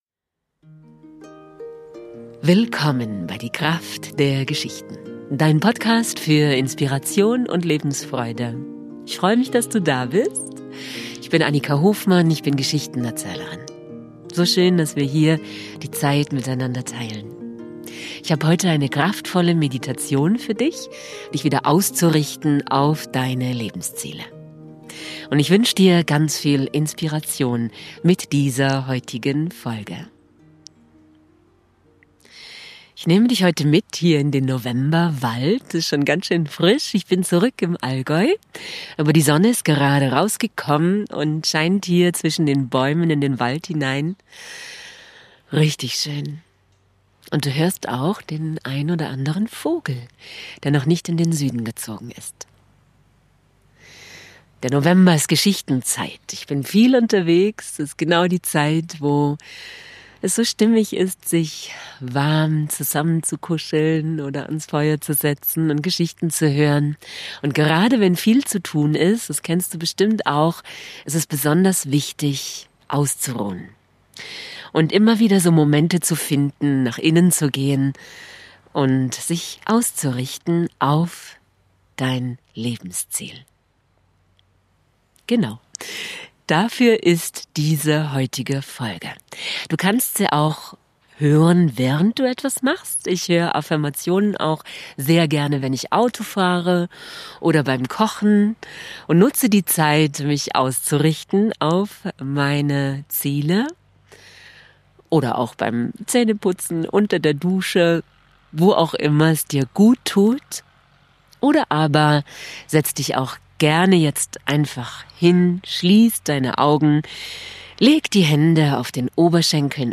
Heute habe ich eine kraftvolle Meditation für Dich, Dich wieder auszurichten auf Deine Lebensziele. Besonders wenn wir viel zu tun haben, sind Momente des Kräfte sammelns so wichtig.